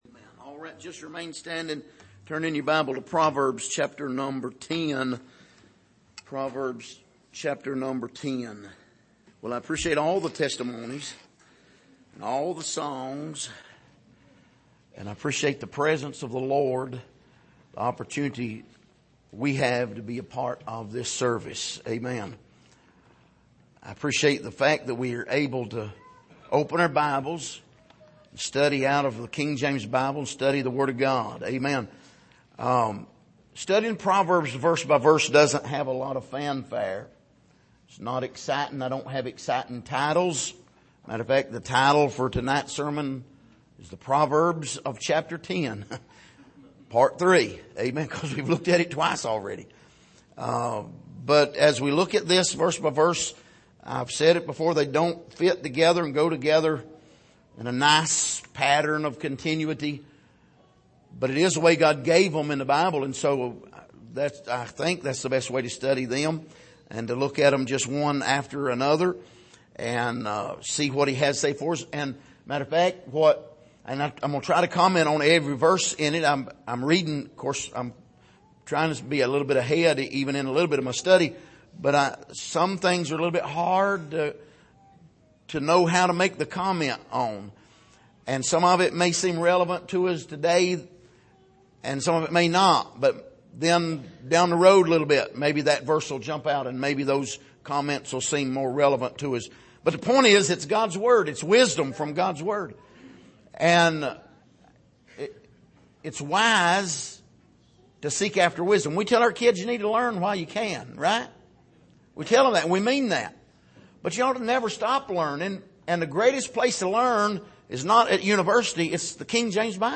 Passage: Proverbs 10:23-32 Service: Sunday Evening